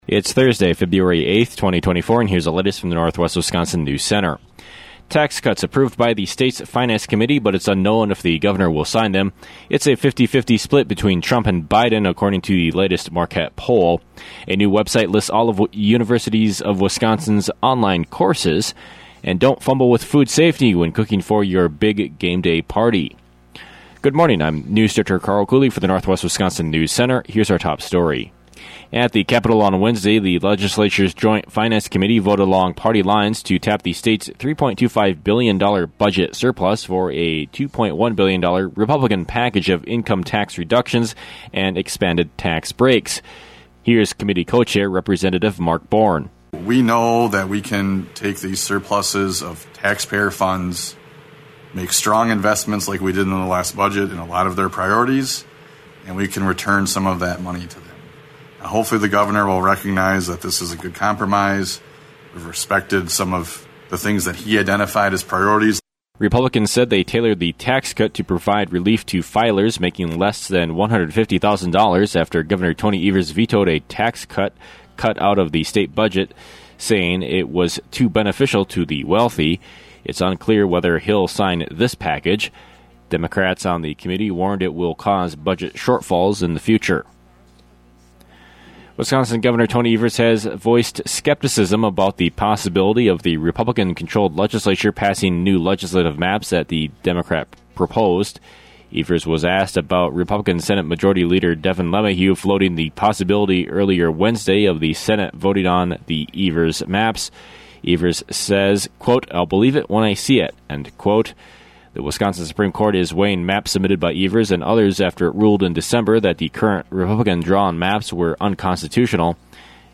AM NEWSCAST – Thursday, Feb. 8, 2024